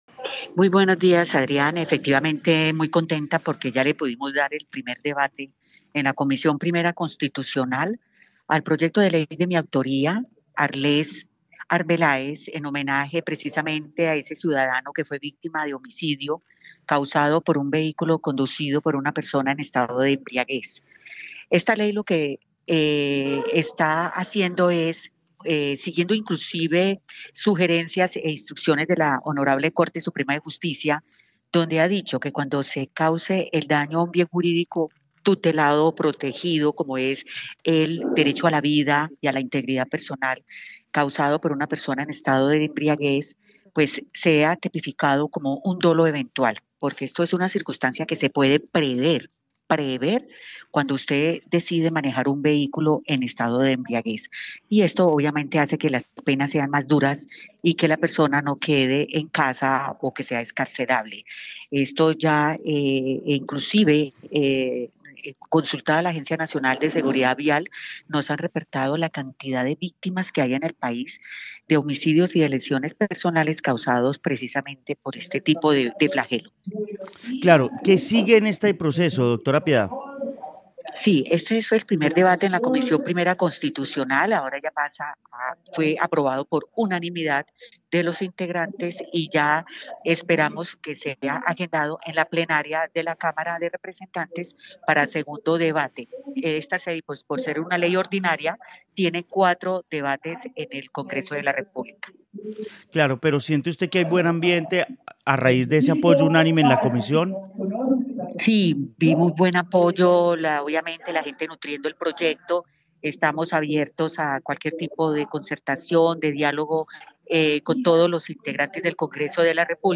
Piedad Correal, Representante a la Cámara del Quindío